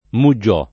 [ mu JJ0+ ]